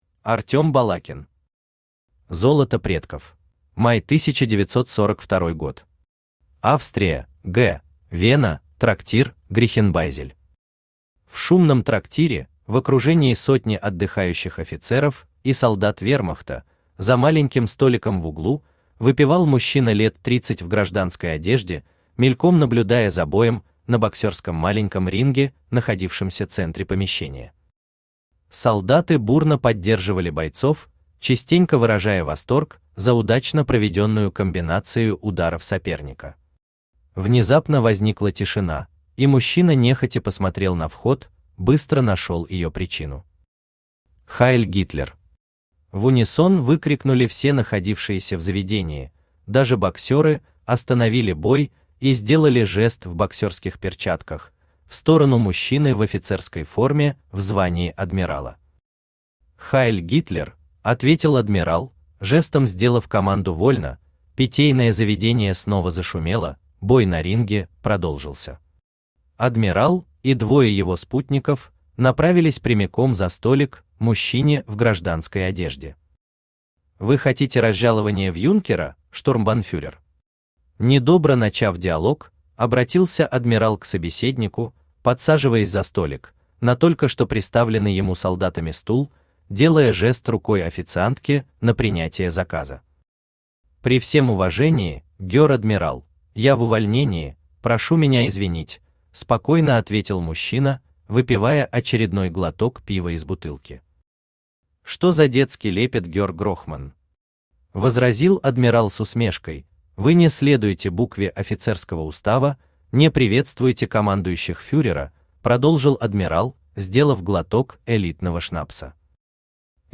Aудиокнига Золото предков Автор Артем Балакин Читает аудиокнигу Авточтец ЛитРес.